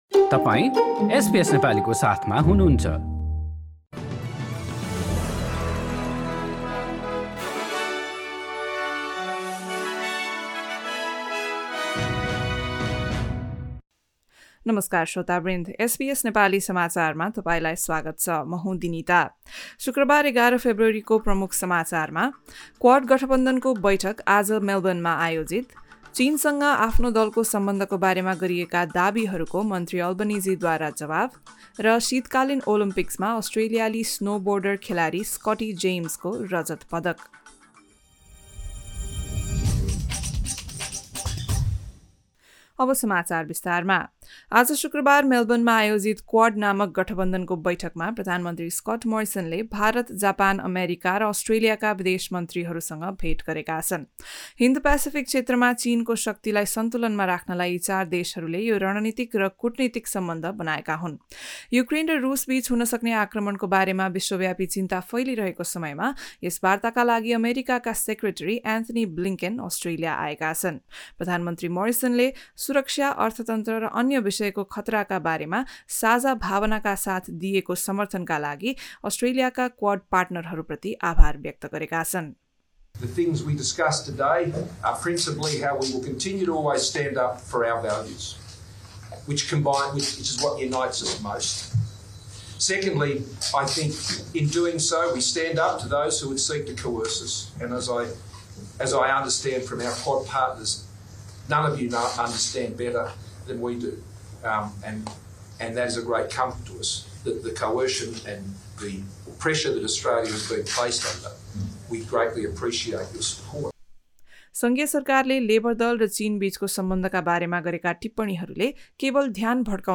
एसबीएस नेपाली अस्ट्रेलिया समाचार: शुक्रबार ११ फेब्रुअरी २०२२